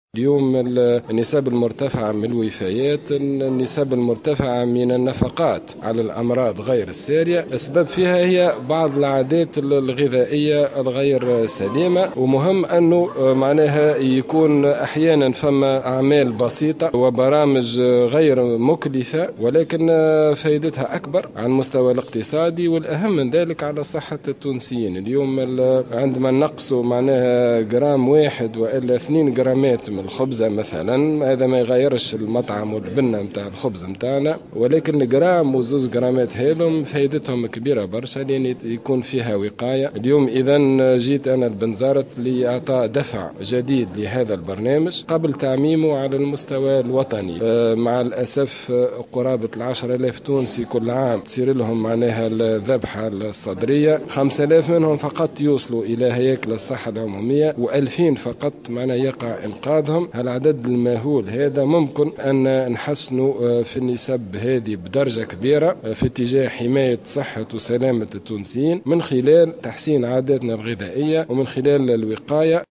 أكد وزير الصحة عماد الحمامي في تصريح لمراسل الجوهرة "اف ام" ببنزرت اليوم الثلاثاء 23 جانفي 2017 أن السبب الرئيس للنسب المرتفعة للوفيات والنفقات على الأمراض غير السارية اليوم يعود إلى عادات غذائية غير سليمة .